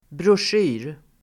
Uttal: [brosj'y:r]